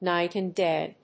アクセントníght and dáy